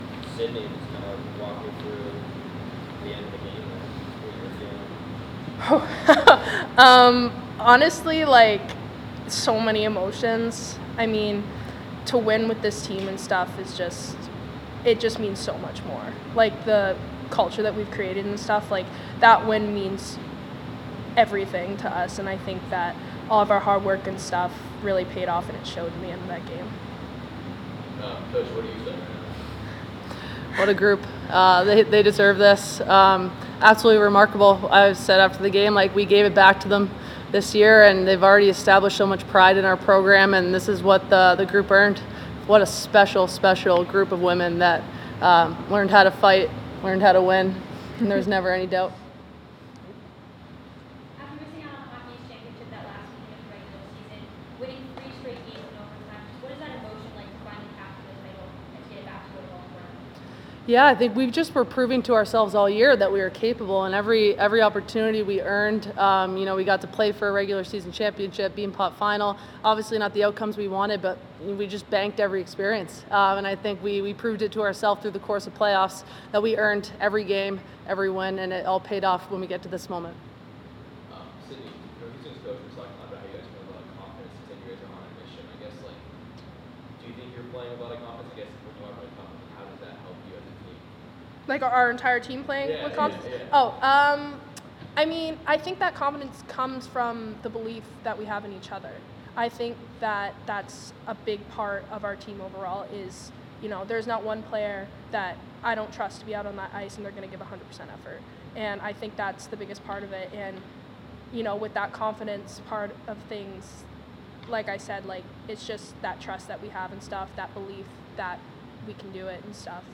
2025 Hockey East Championship Postgame Press Conference (3-8-25)